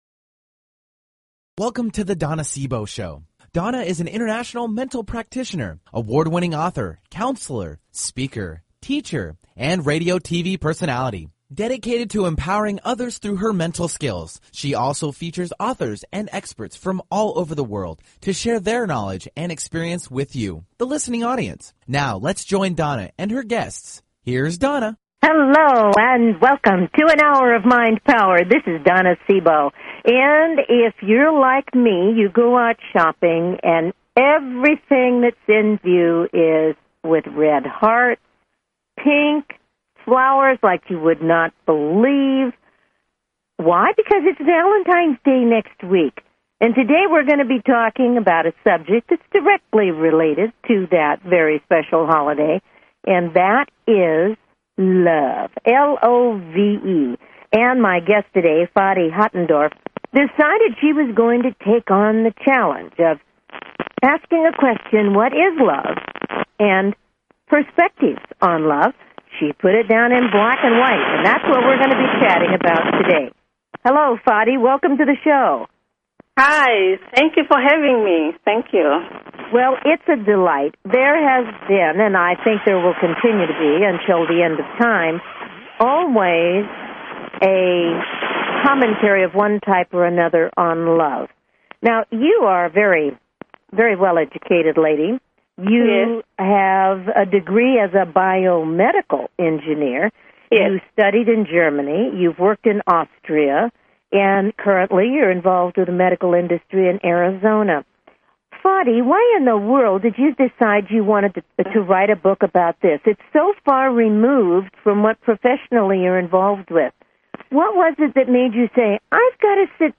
Talk Show Episode
Callers are welcome to call in for a live on air psychic reading during the second half hour of each show.